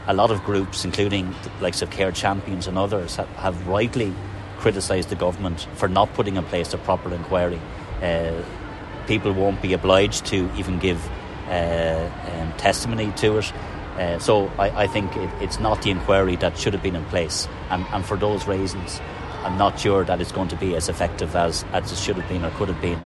Sinn Fein’s David Cullinane says families are still looking for answers and feel the Government’s planned review isn’t enough: